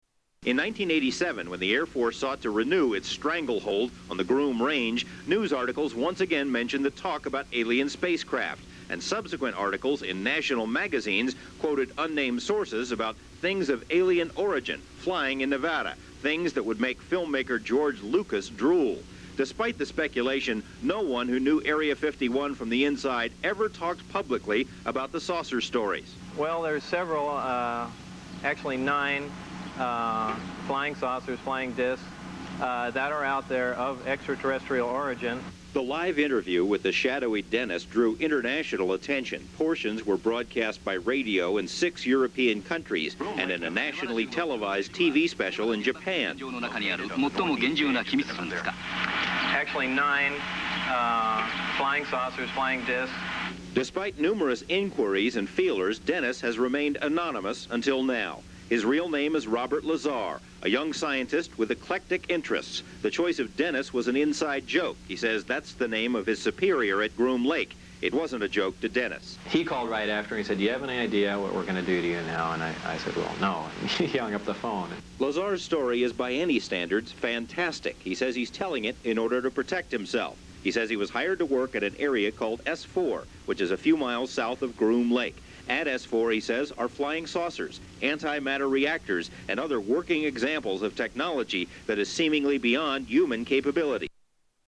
Bob Lazar first interview 1989